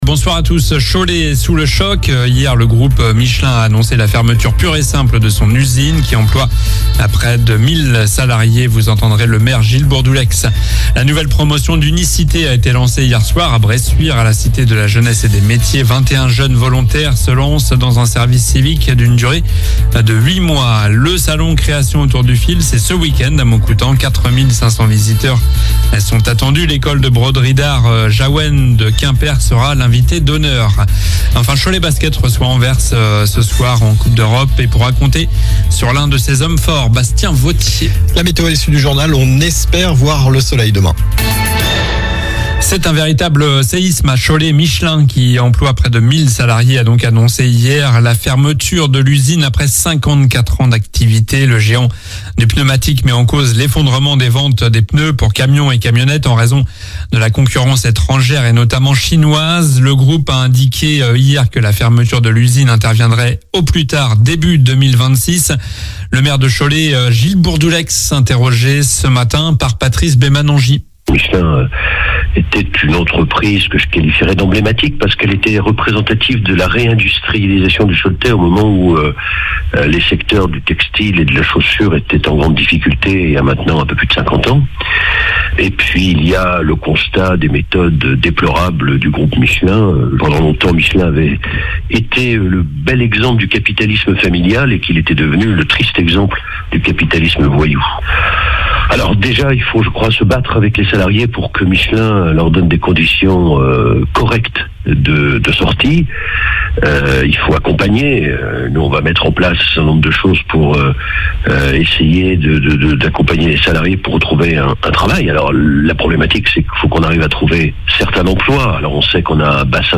Journal du mercredi 06 novembre (soir)
- Michelin a annoncé la fermeture pure et simple de son usine de Cholet. Vous entendrez le maire Gilles Bourdouleix.